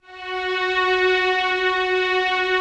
Index of /90_sSampleCDs/Optical Media International - Sonic Images Library/SI1_Swell String/SI1_Mello Swell